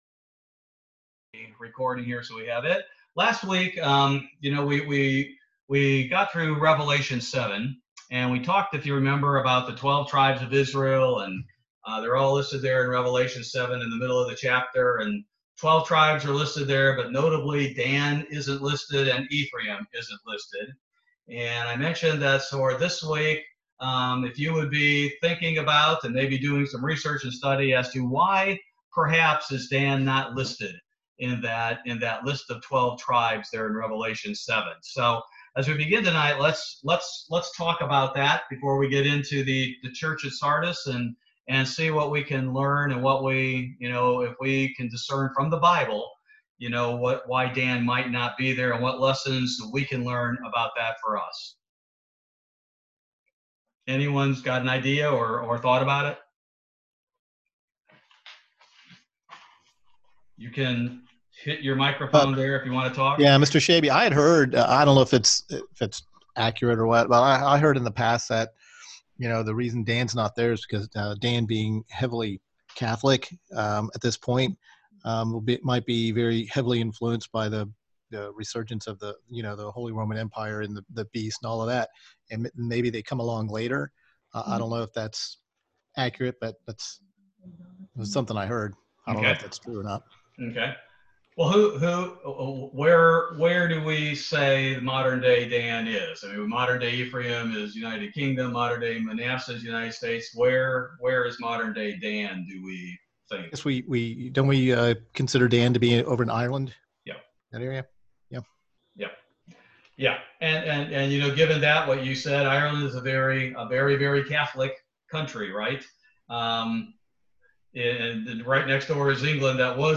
Bible Study - August 5, 2020